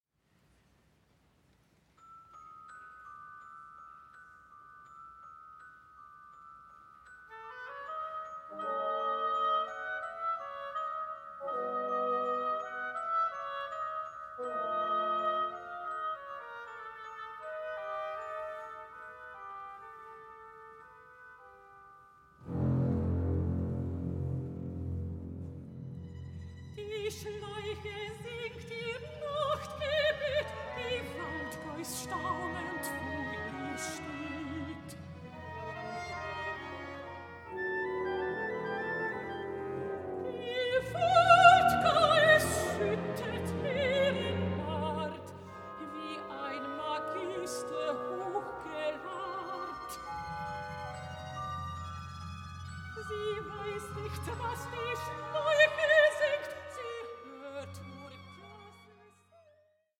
mezzo-soprano